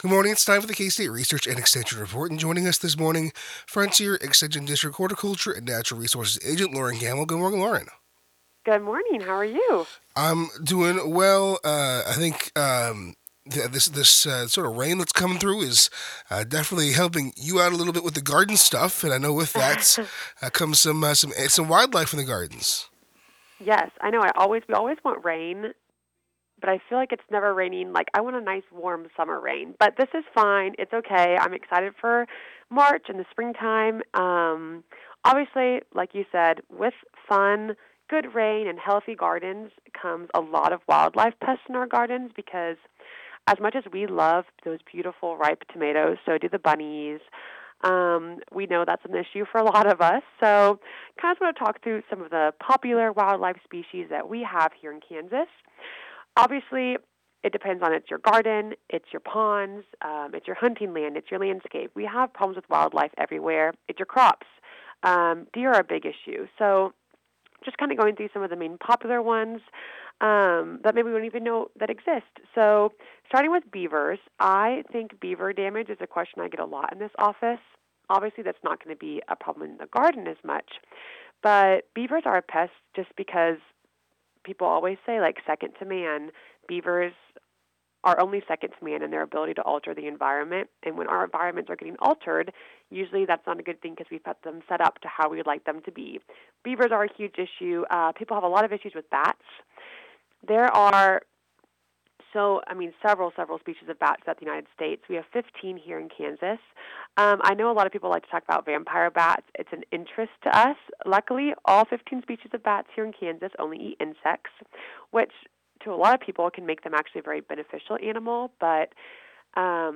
KOFO Radio 2026 Recordings – Local Broadcast Audio Archive